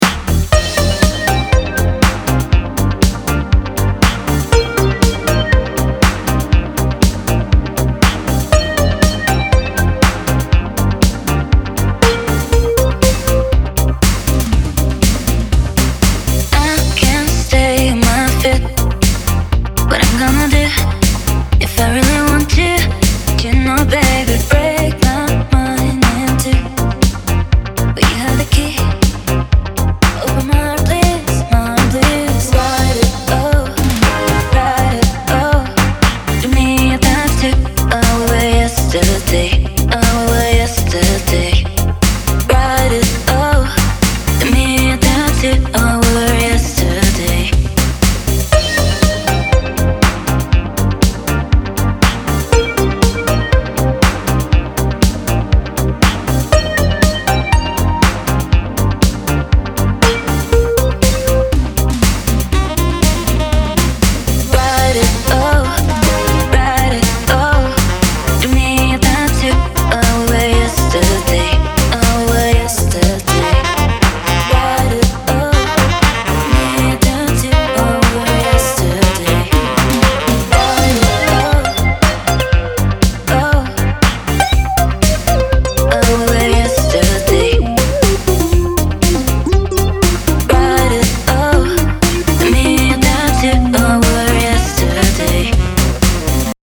сделал компактный по пространству ) вариант